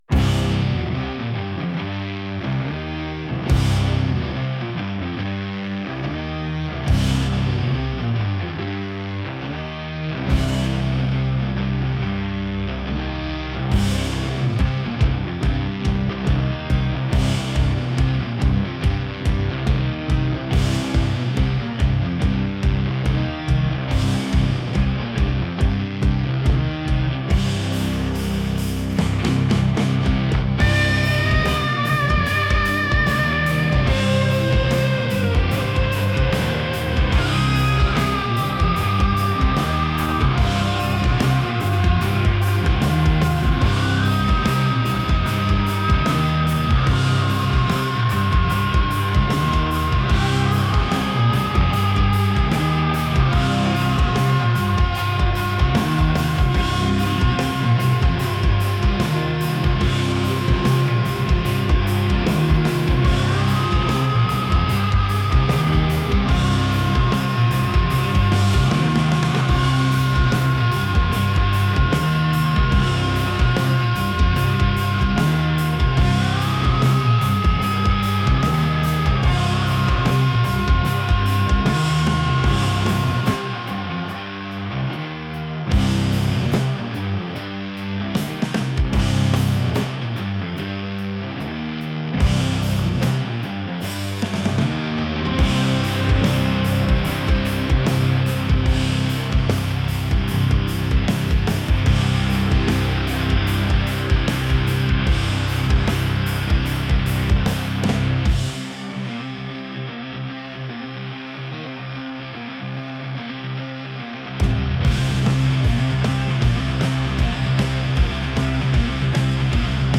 heavy | rock | groovy